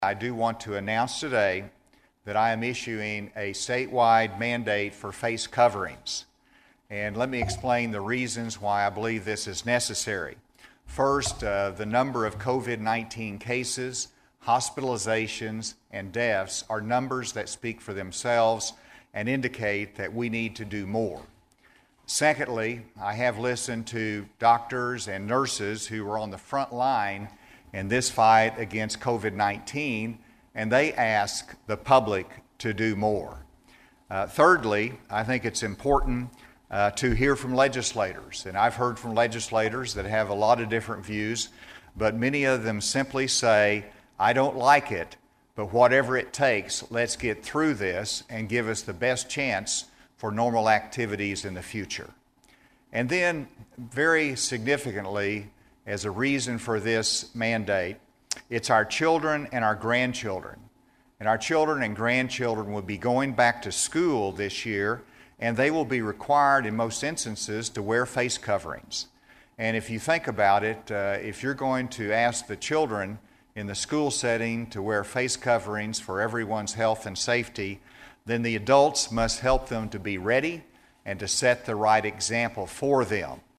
Arkansas Governor Asa Hutchinson will sign an executive order requiring masks statewide. He made the announcement at his briefing Thursday in Little Rock.